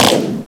MGUN1.WAV